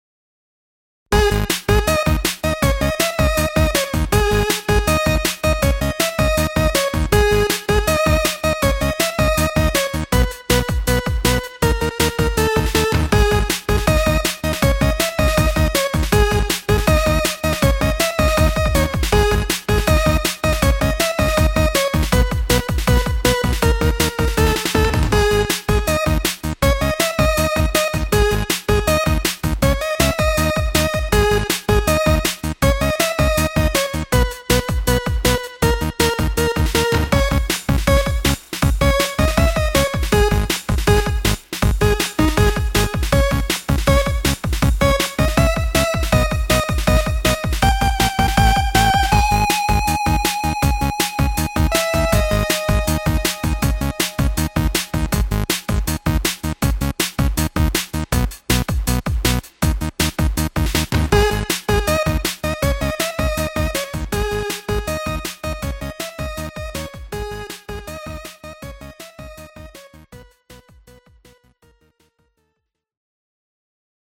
Gameplay music